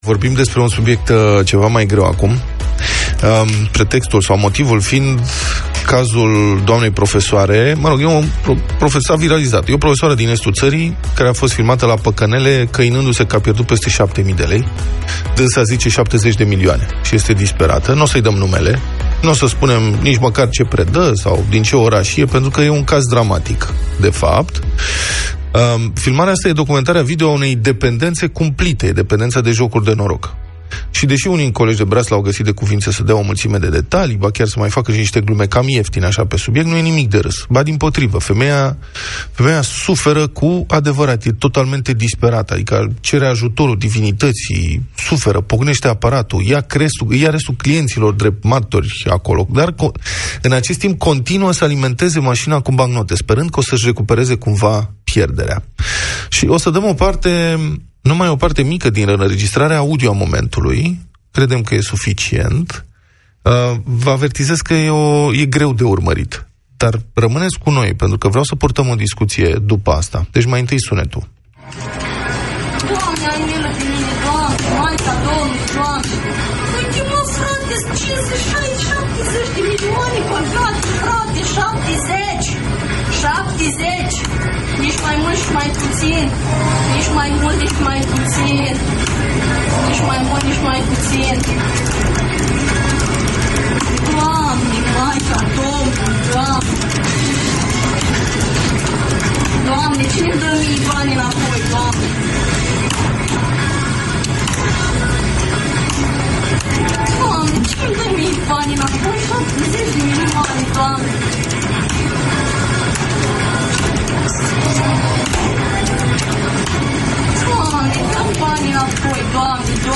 17-MAR-DEPENDENTA-DE-PACANELE-DEBATE.mp3